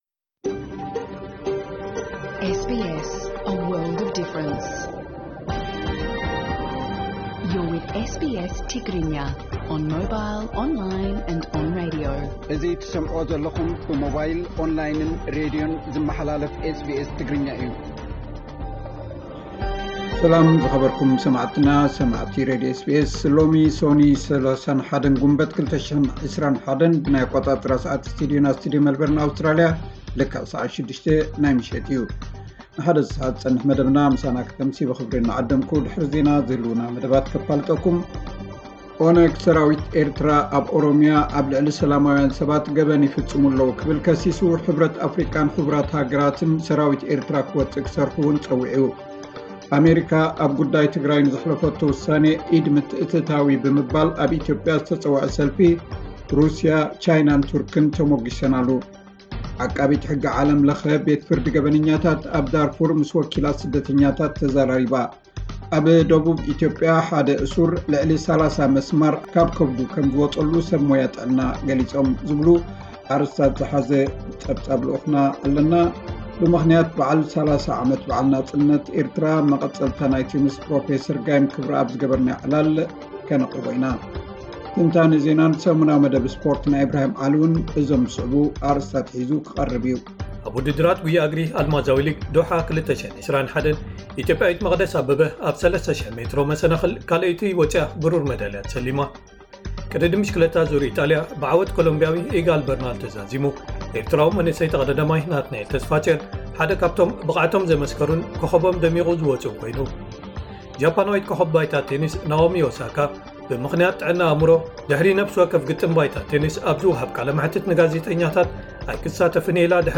ዕለታዊ ዜና 31 ግንቦት 2021 SBS ትግርኛ